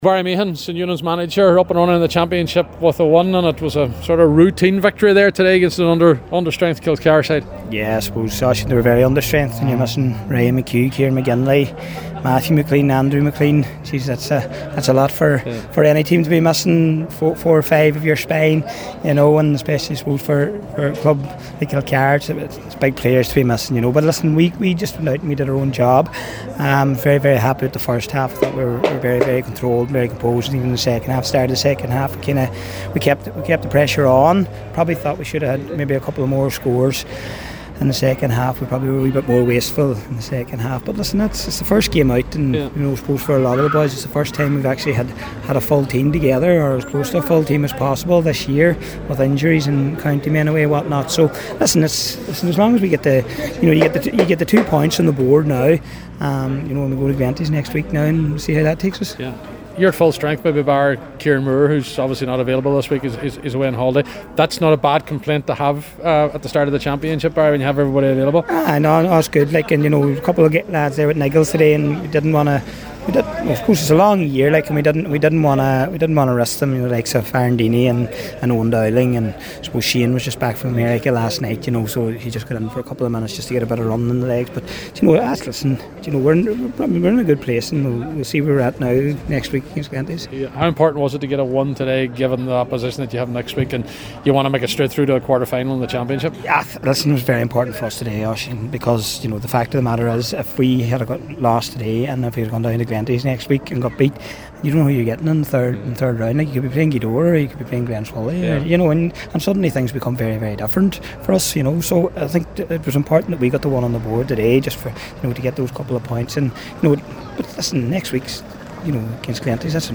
after full time at the O’Donnell Park…